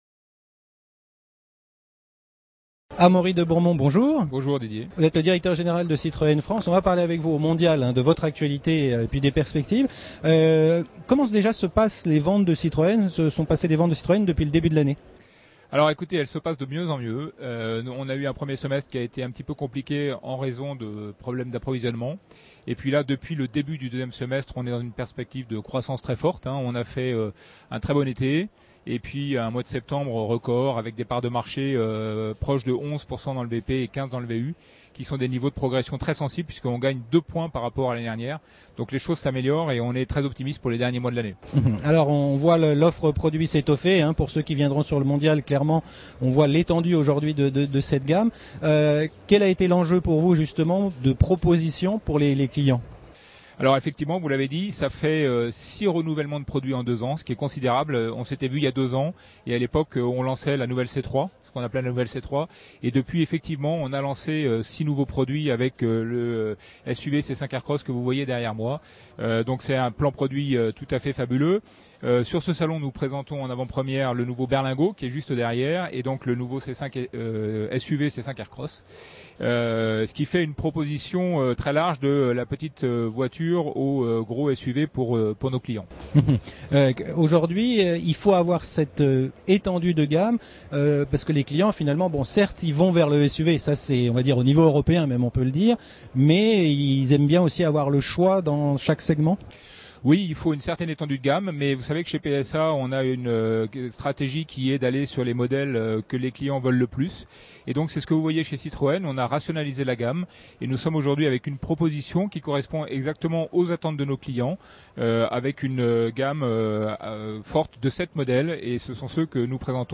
La Web TV au Mondial de l'Automobile Paris 2018